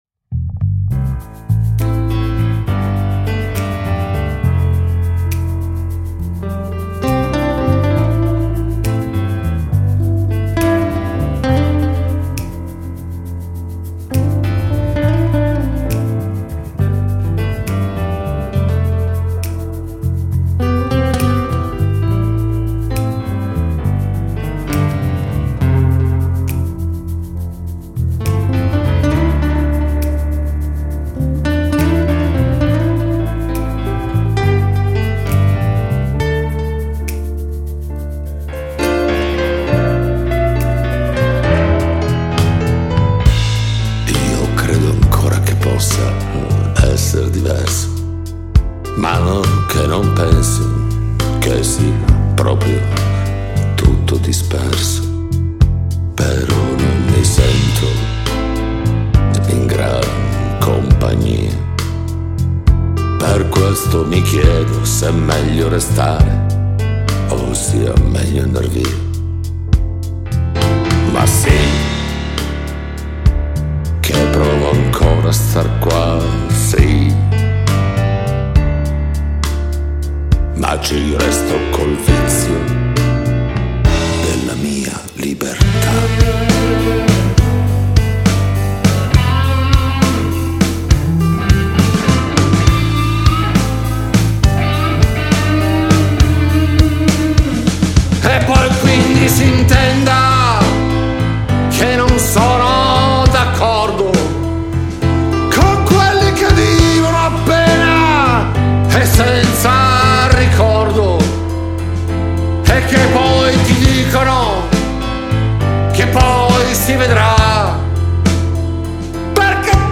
una band acustica
Lead Vocal
Guitars
Percussions
Bass